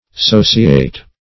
Sociate \So"ci*ate\, n.